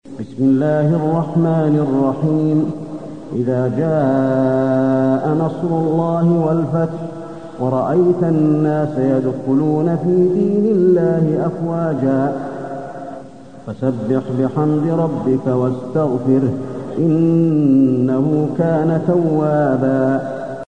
المكان: المسجد النبوي النصر